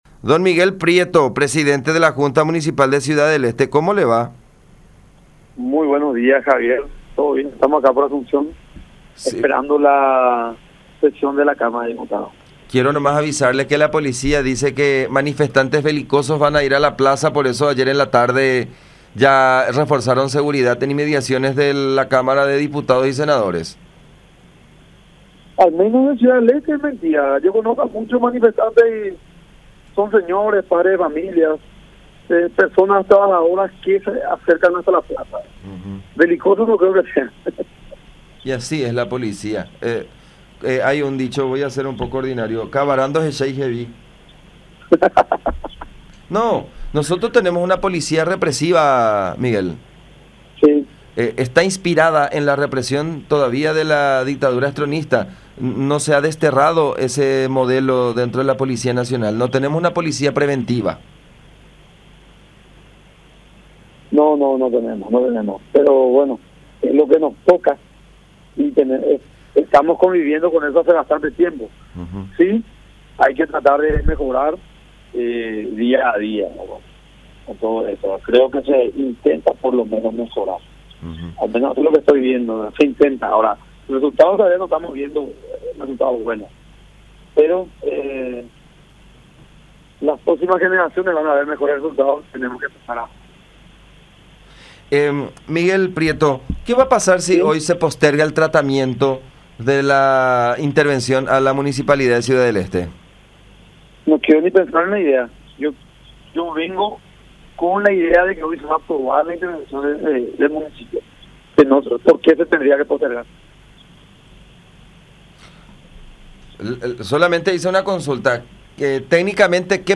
“No quiero ni pensar en la idea de que no se apruebe la intervención (por la Cámara Baja), no veo razón de que pase”, expresó el titular del legislativo esteño  en comunicación con La Unión, sosteniendo que McLeod tiene “mucho para responder”.